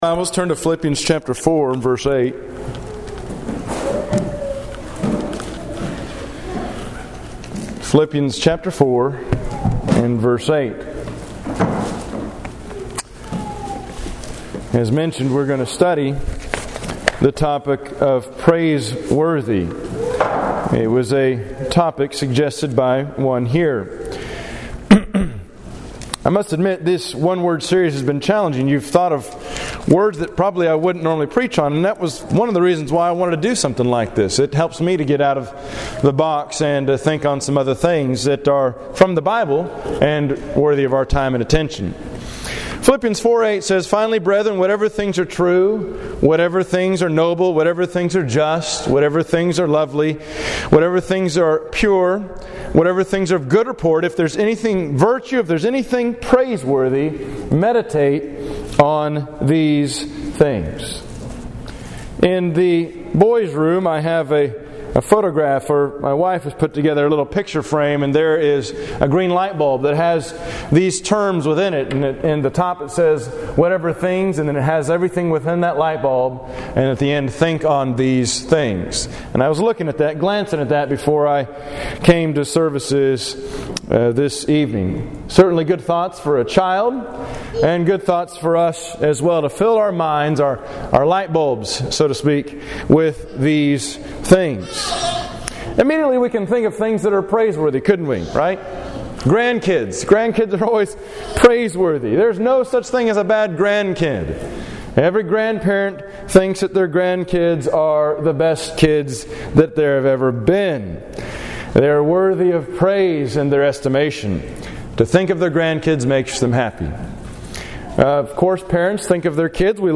2015 Sermons